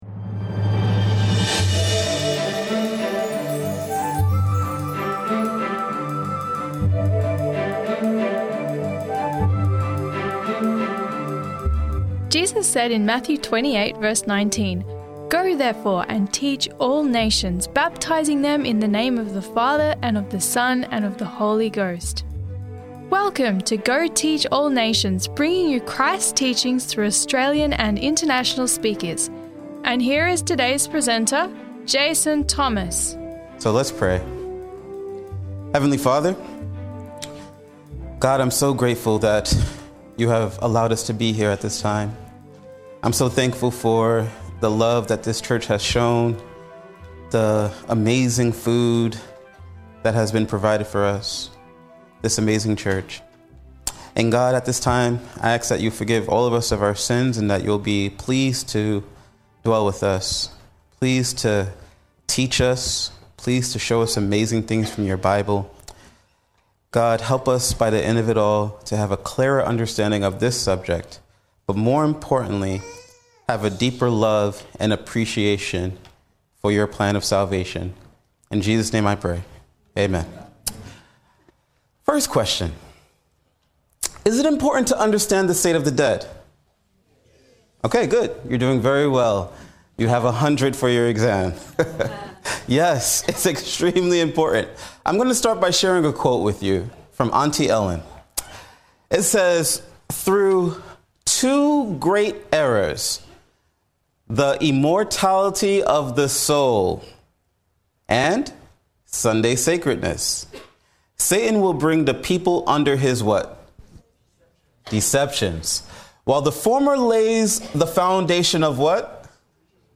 What Really Happens When We Die? Sermon Audio 2606
This message was made available by the Waitara Seventh-day Adventist church.